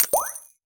potion_flask_mana_collect_02.wav